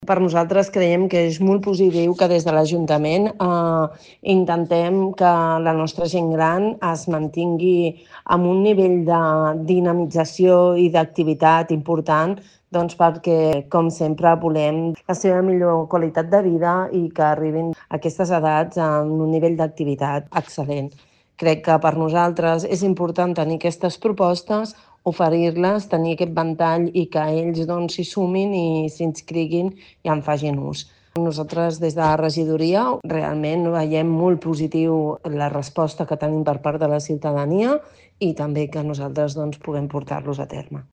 Montserrat Salas, regidora de Gent Gran de l'Ajuntament de Martorell